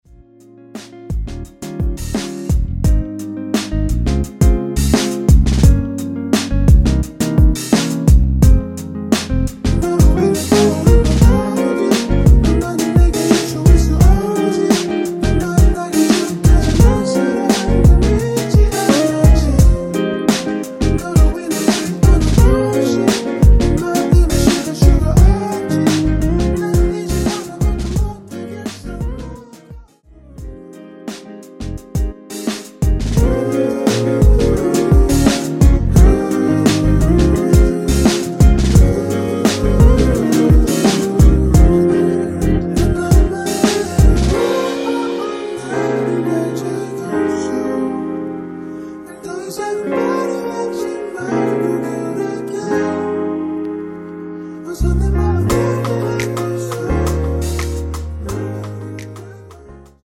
원키에서(-2)내린 코러스 포함된 MR입니다.(미리듣기 참조)
Fm
◈ 곡명 옆 (-1)은 반음 내림, (+1)은 반음 올림 입니다.
앞부분30초, 뒷부분30초씩 편집해서 올려 드리고 있습니다.
중간에 음이 끈어지고 다시 나오는 이유는